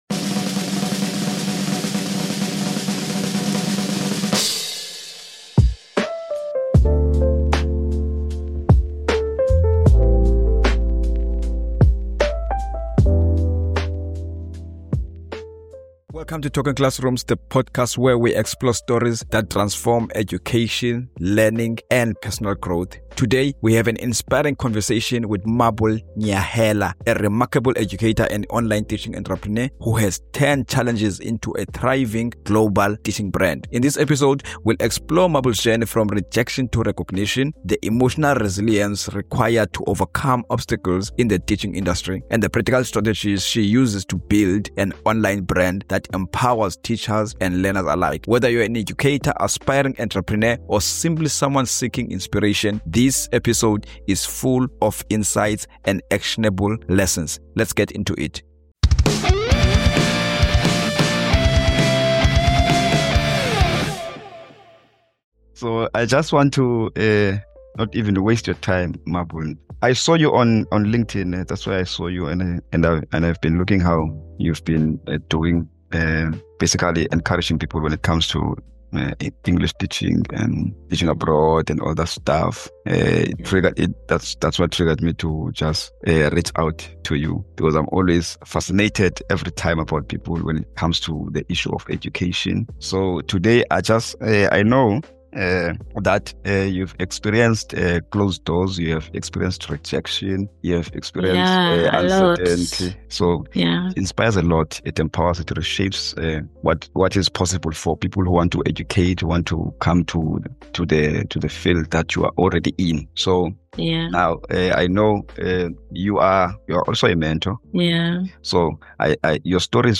This conversation goes beyond technique.